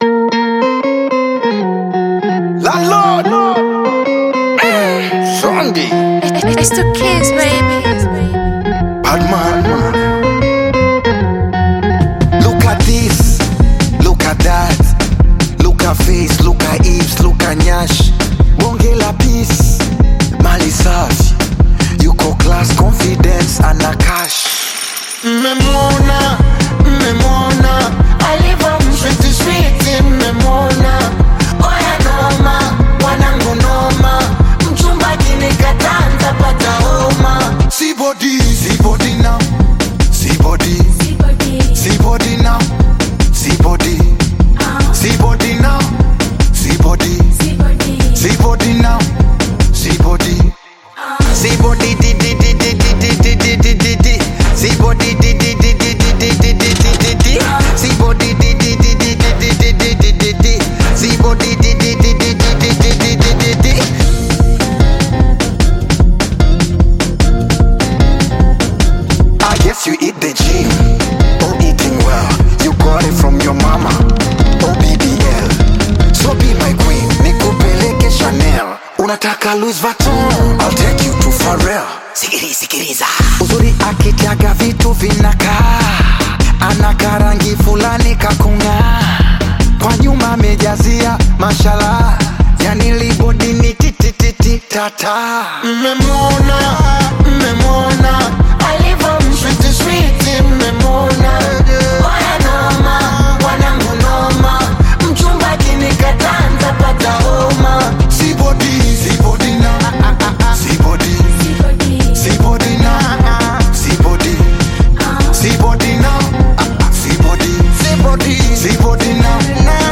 smooth Afro-Beat/Bongo Flava single